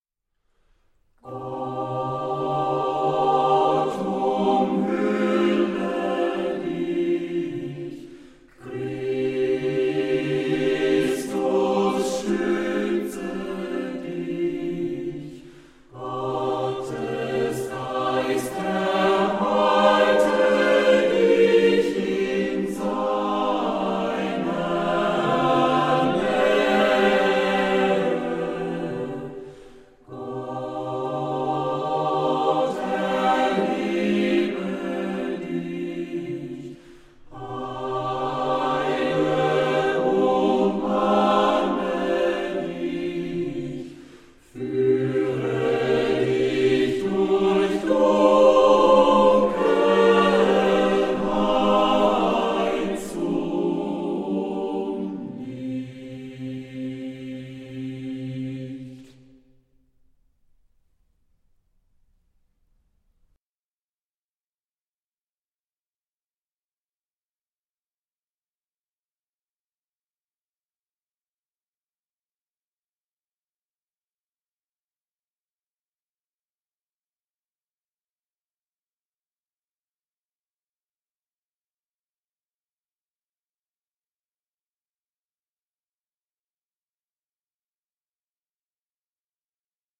Diese CD liefert 22 Titel für Jugend- und Gemeindechöre.
• Sachgebiet: Chormusik/Evangeliumslieder